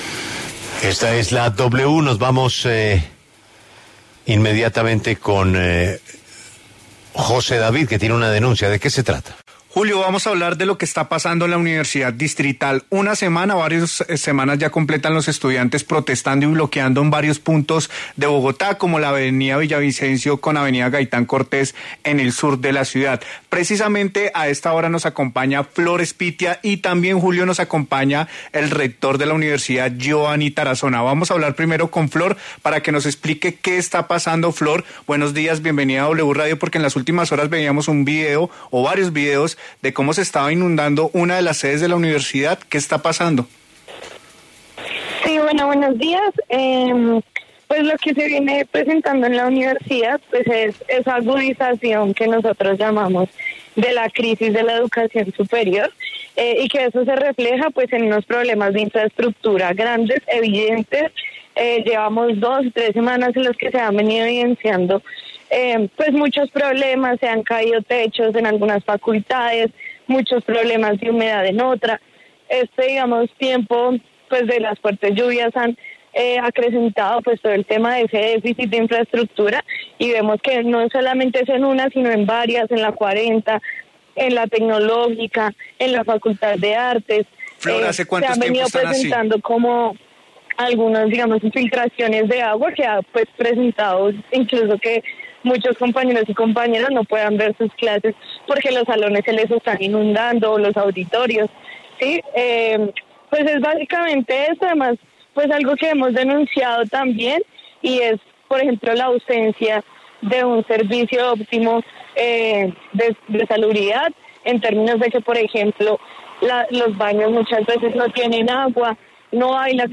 En entrevista con La W